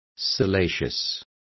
Complete with pronunciation of the translation of salacious.